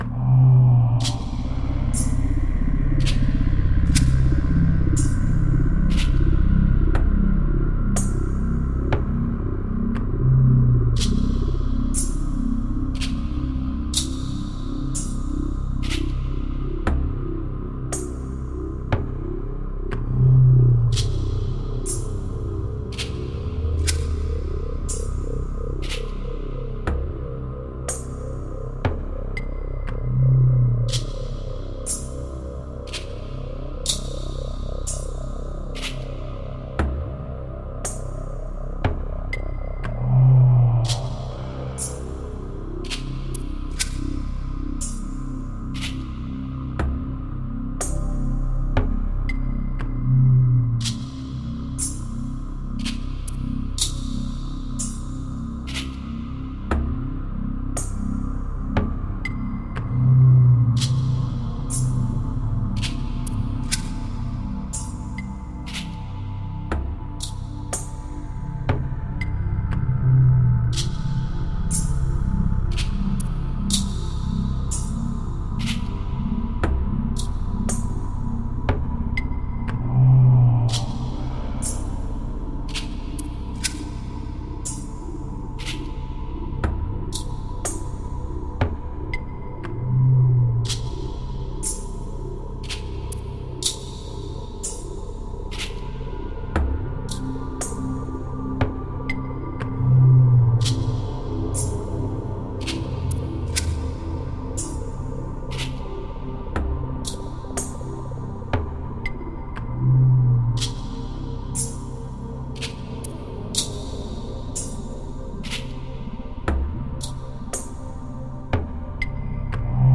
Beautiful ambient space music.
Tagged as: Ambient, New Age, Space Music